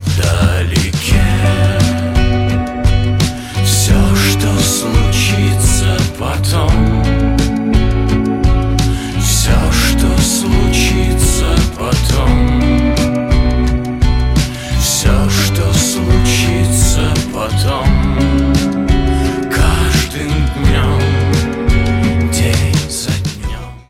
рок